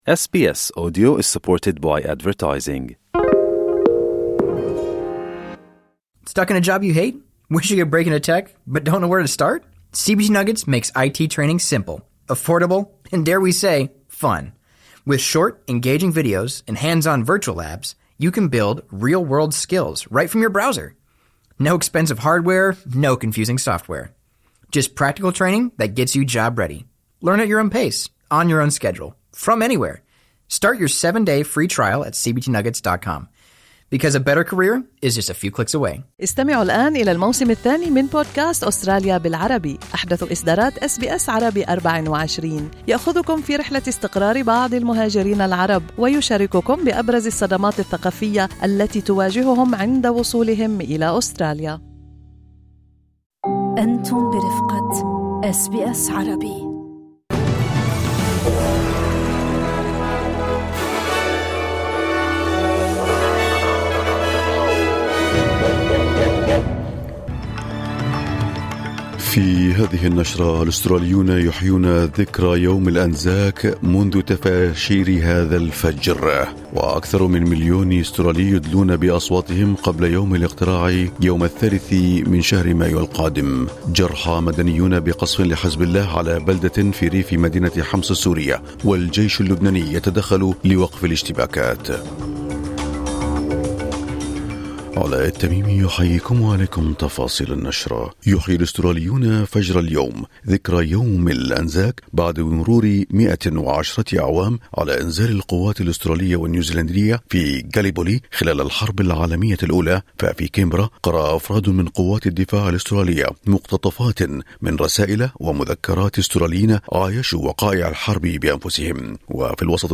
نشرة أخبار الصباح 25/5/2025